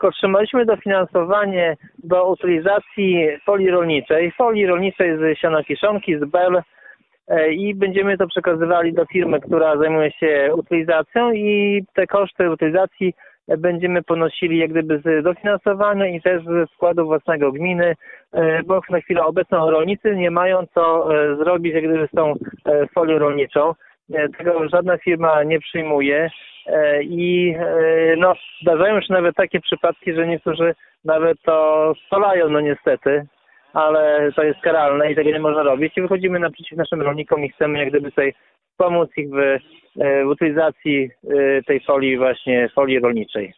O szczegółach mówi Zbigniew Mackiewicz, wójt gminy Suwałki.